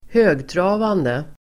Uttal: [²h'ö:gtra:vande]